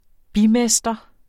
Udtale [ ˈbi- ]